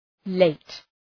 Προφορά
{leıt}